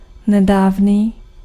Ääntäminen
IPA: /ny/